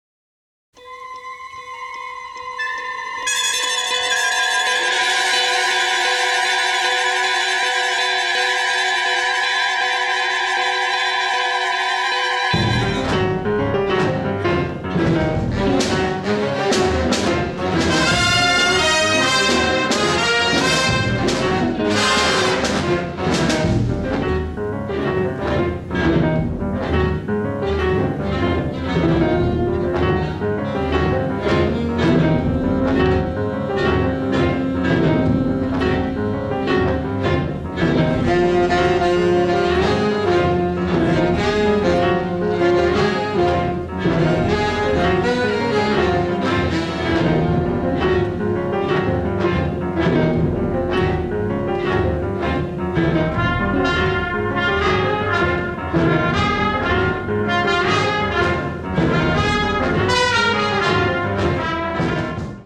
diegetic jazz and 1950s-styled dance tunes